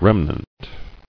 [rem·nant]